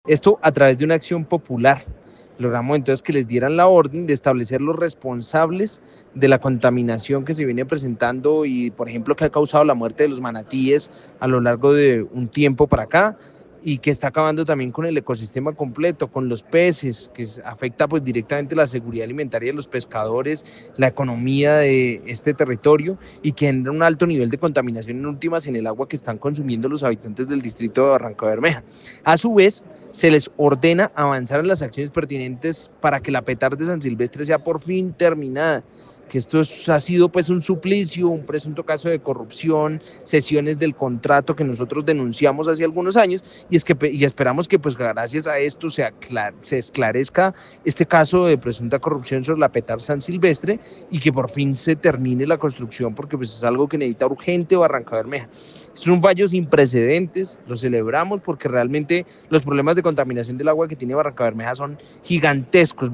Cristian Avendaño, representante a la Cámara por Santander